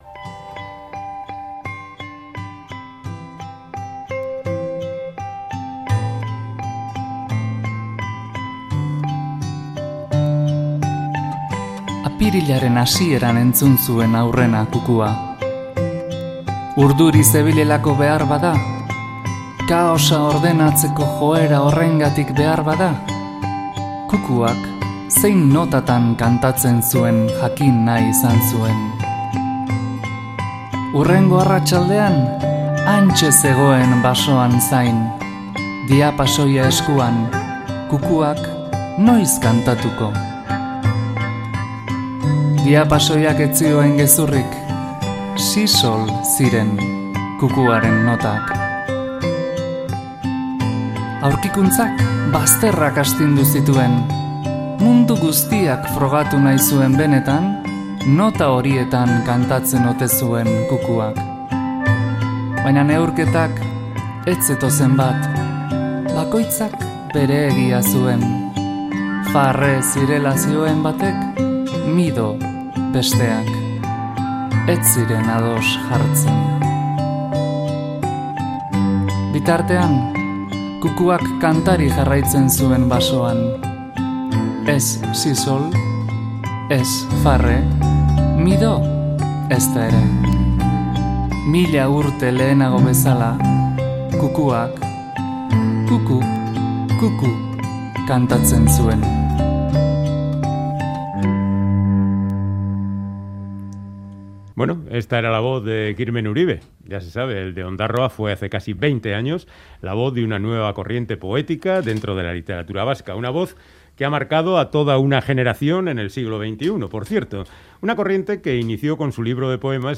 Audio: Charlamos con Kirmen Uribe tras la publicación al castellano de su último libro de poemas, "17 segundo", un libro que recoge diecisiete años de poesía